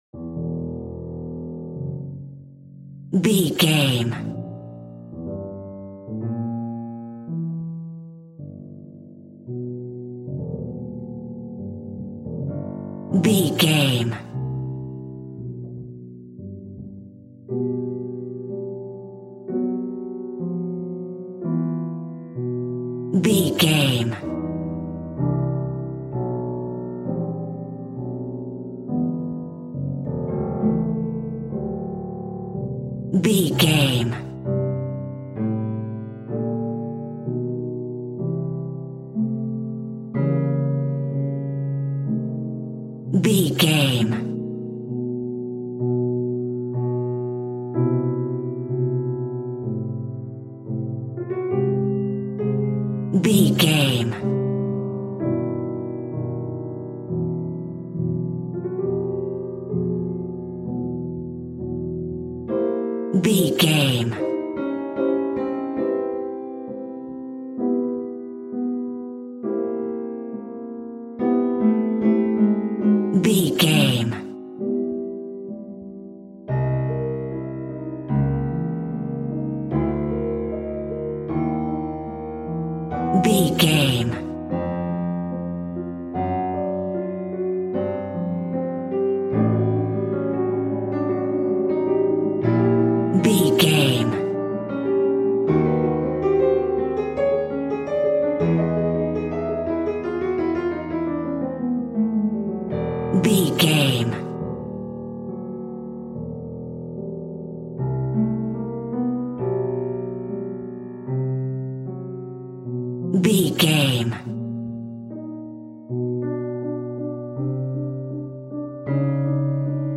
Thriller Piano Music Cue.
Aeolian/Minor
ominous
dark
suspense
eerie